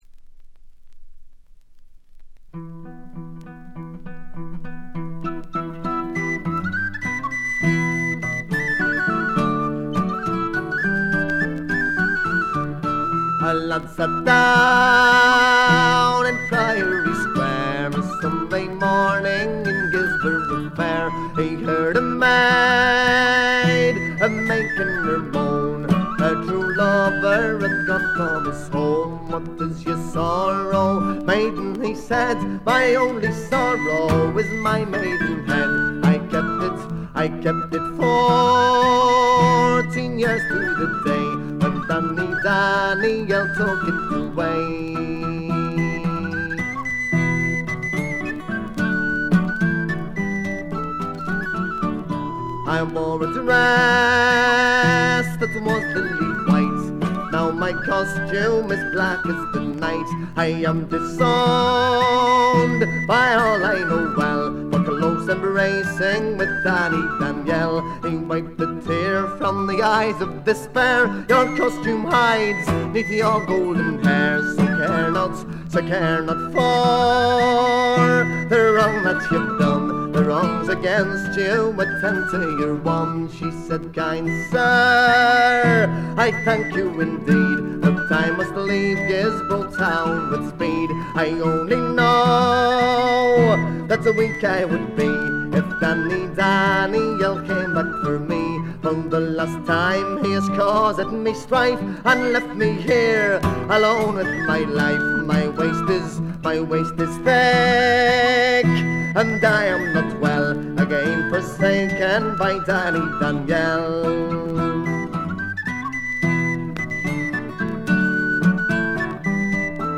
軽微なバックグラウンドノイズにところどころでチリプチ（特にA1〜A3）。
試聴曲は現品からの取り込み音源です。
Vocals ?
Guitar ?
Tin Whistle ?